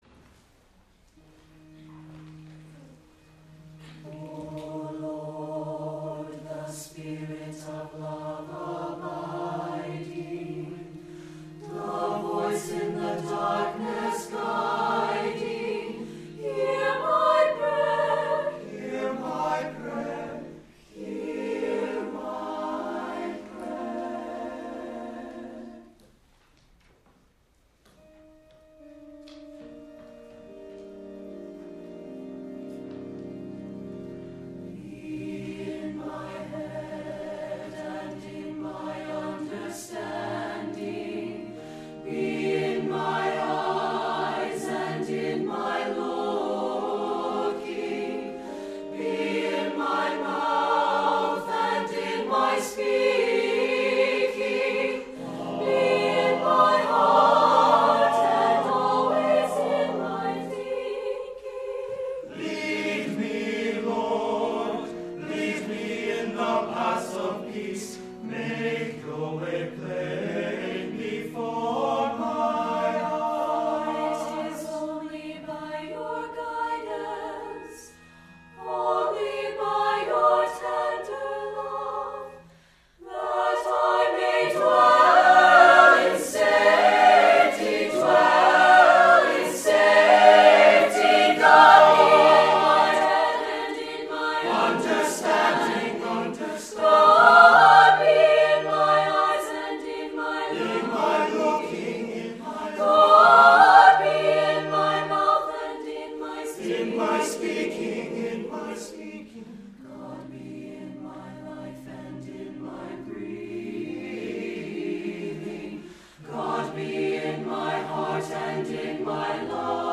for SATB Choir and Organ or Piano (2004)